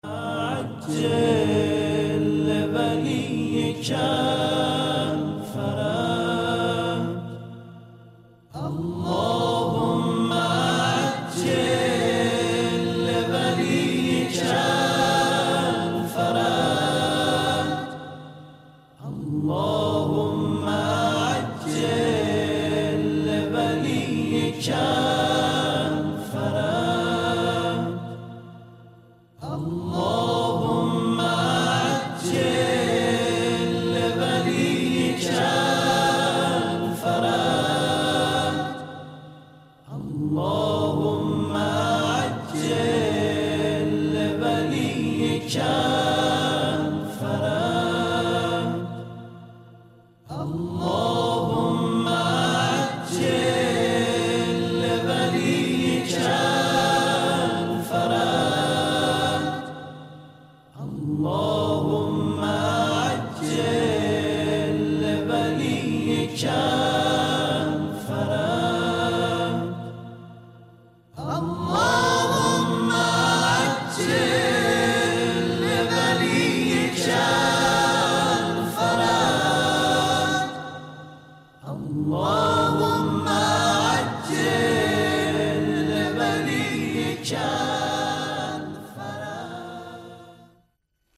فایل صوتی همخوانی دعای اللهم عجل لولیک الفرج
پیرو درخواست یکی از دوستان فایل صوتی همخوانی دعای اللهم عجل لولیک الفرج که سحر از شبکه یک پخش می شه رو برای شما دوستان قرار دادم.